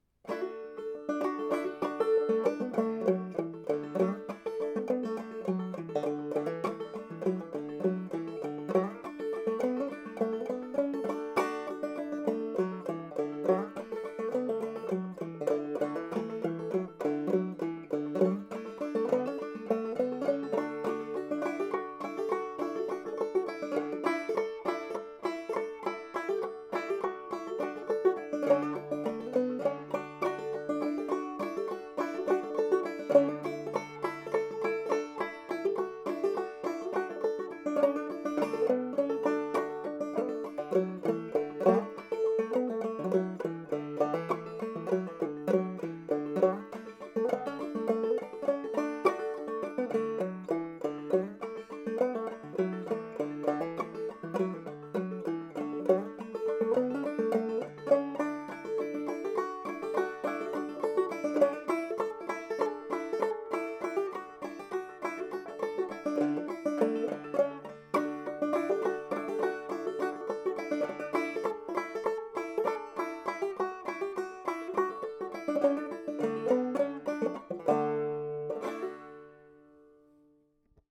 Tippy Get Your Hair Cut (w/chordal accompaniment).
Banjo Hangout Newest 100 Clawhammer and Old-Time Songs
… continue reading 234 episodes # Music # Old Time # Banjo Hangout # Banjo Hangout Members